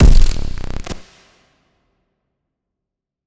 TM88 FunkKick4.wav